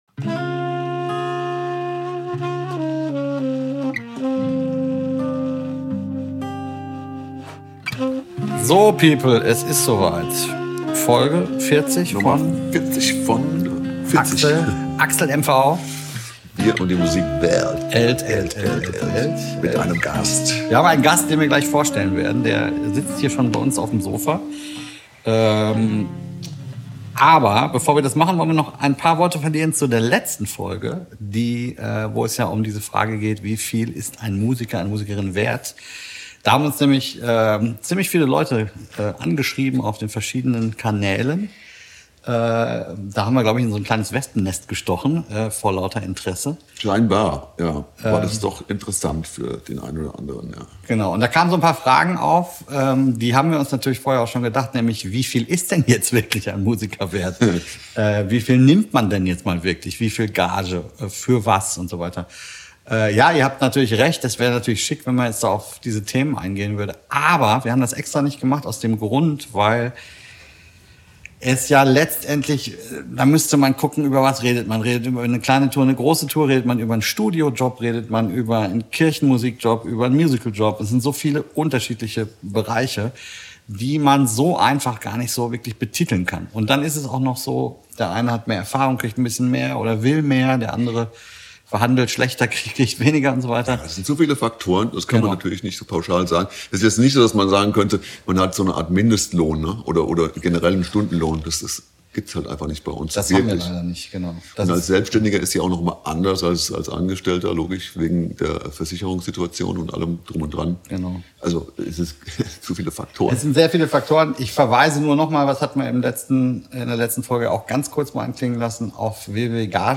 Beschreibung vor 1 Jahr Folge 40 von AXLMV - Wir und die Musikwelt kommt direkt aus Südafrika.